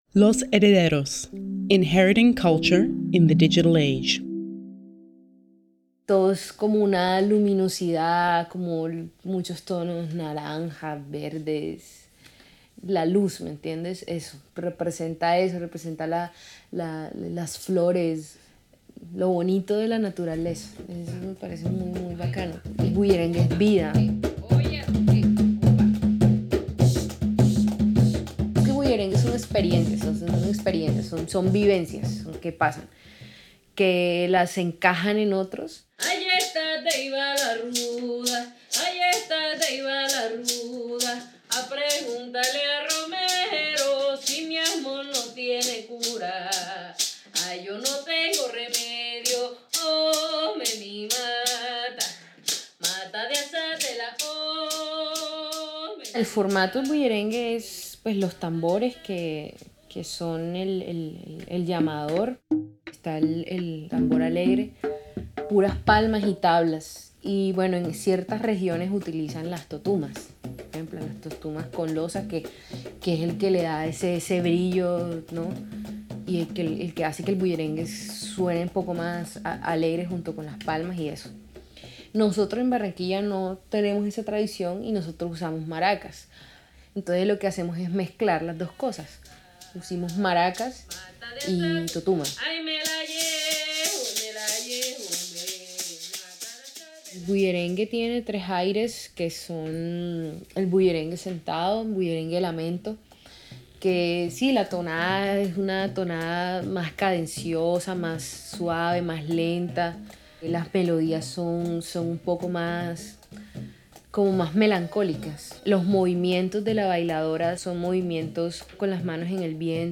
commenting on bullerengue style